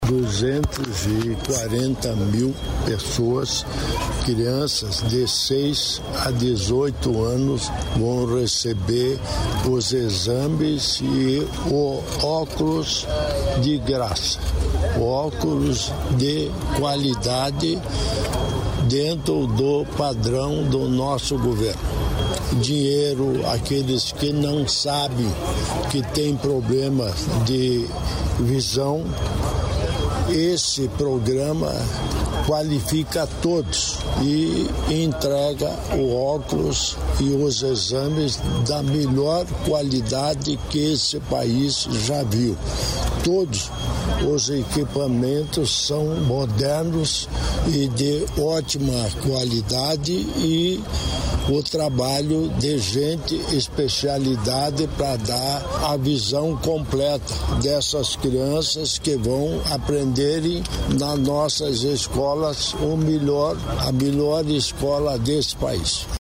Sonora do governador em exercício, Darci Piana, sobre o programa Bons Olhos